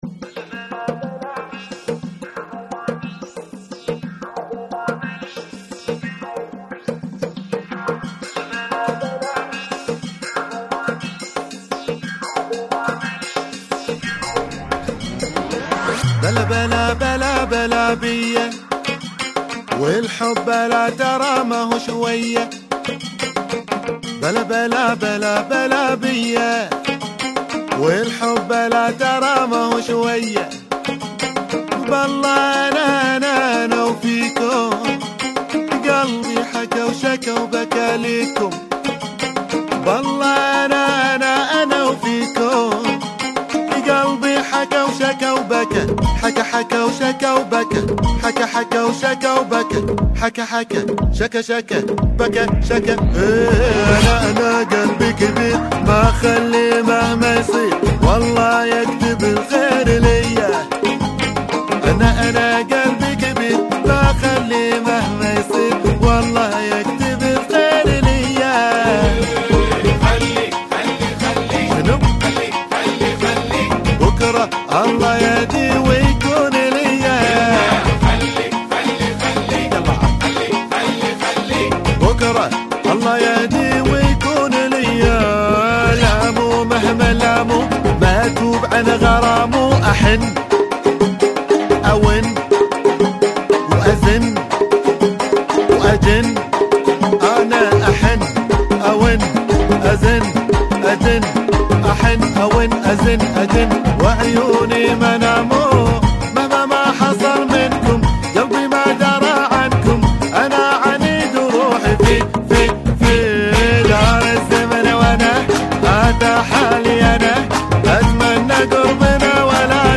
120 bpm